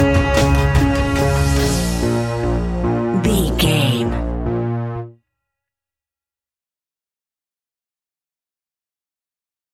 Aeolian/Minor
B♭
ominous
dark
suspense
eerie
synthesiser
drums
ticking
electronic music